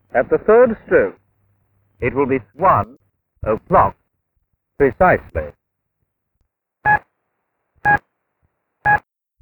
TIM 2015 Speaking Clock
Mk II experimental clock with with lower pitched pips. 840Hz pips.